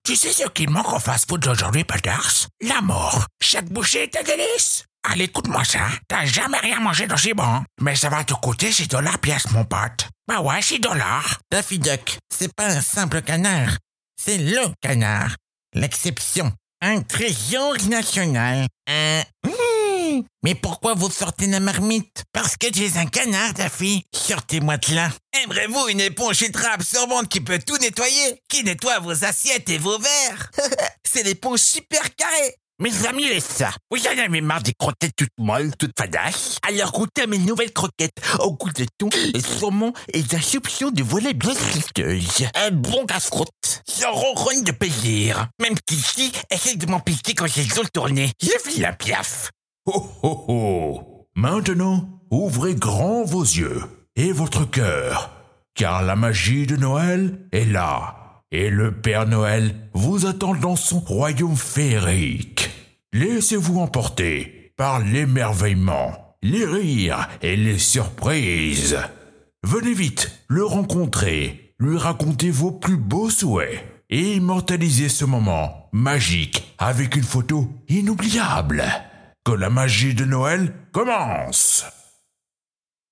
Male
Assured, Authoritative, Character, Confident, Cool, Corporate, Deep, Engaging, Natural, Reassuring, Smooth, Soft, Warm, Versatile, Young
Microphone: se electronics x1s